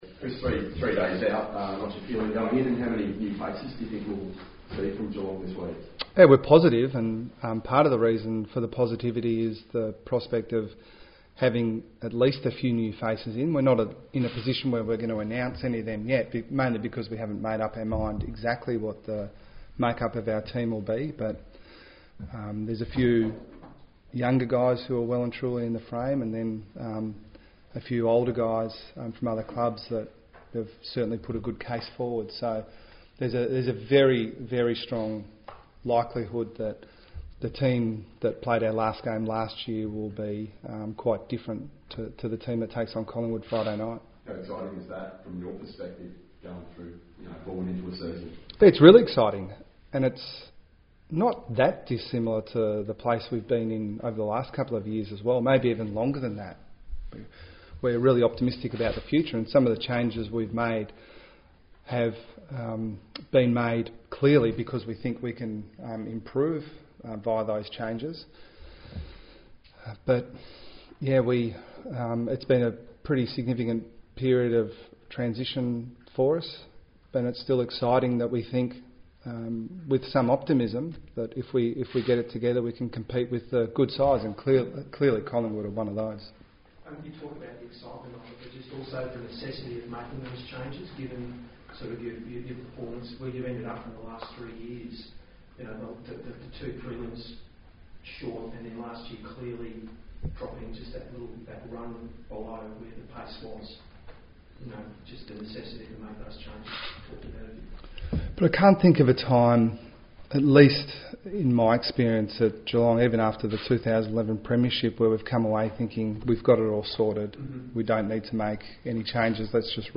Geelong coach Chris Scott faces the media head of the Cats' Round 1 meeting with Collingwood.